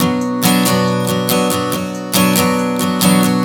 Strum 140 Bm 01.wav